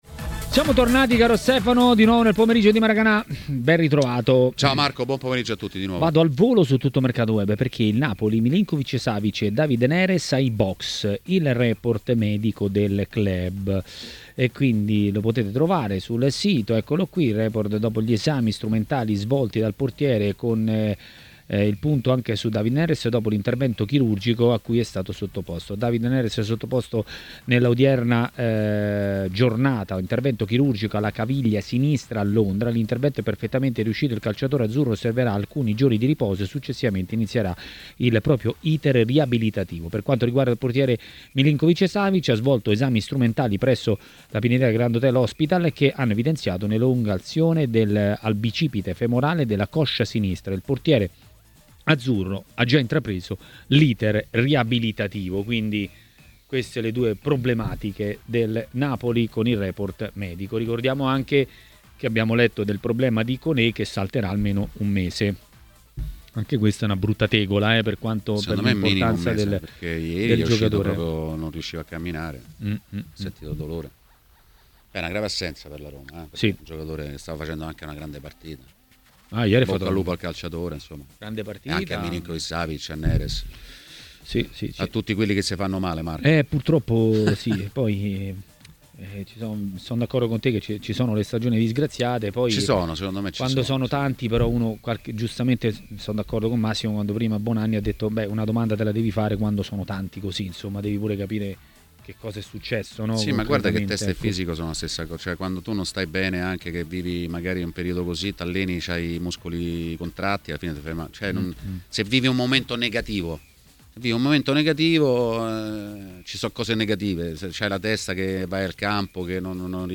L'ex calciatore Massimo Orlando è intervenuto a TMW Radio, durante Maracanà.